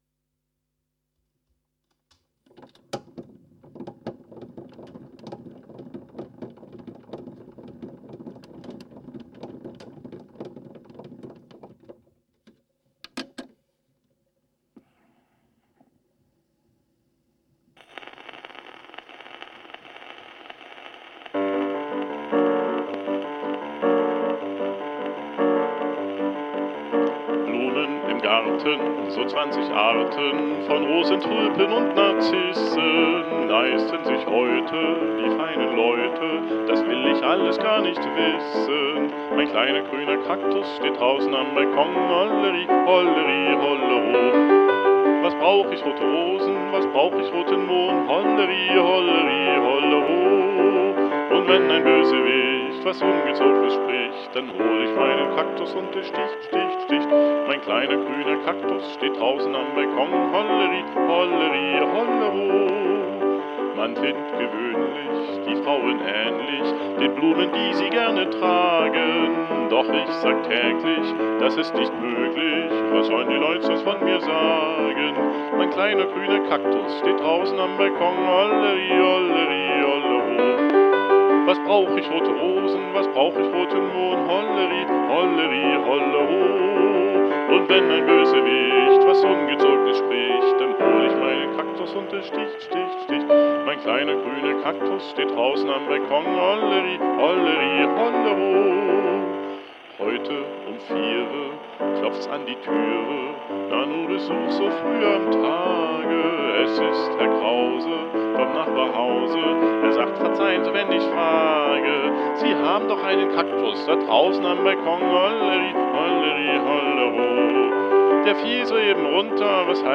z.T. mit "Grammophon-Special-Effect"